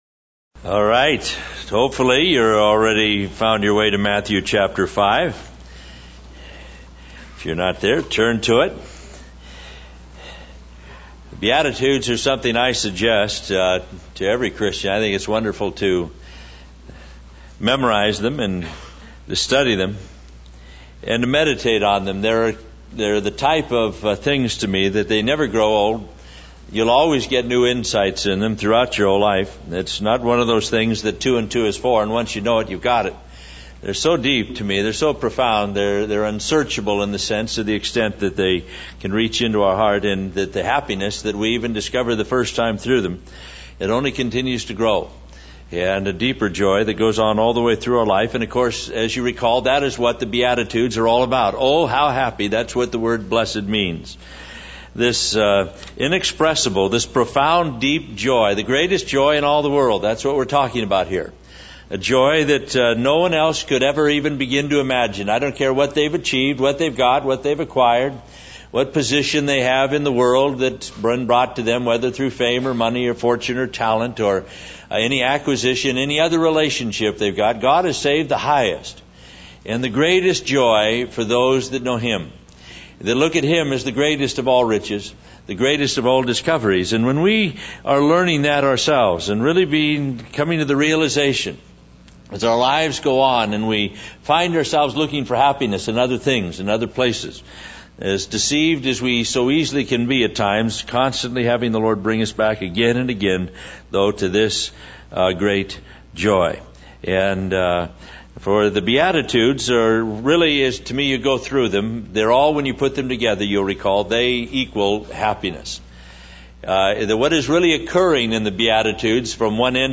In this sermon, the speaker reflects on a personal transformation and the passing of authority from a powerful man to a seemingly insignificant Christian. The speaker acknowledges his previous desire to abuse power and contrasts it with the humble and approachable nature of the person he aspires to be like. The sermon emphasizes the importance of being meek and gentle, even in competitive situations like sports.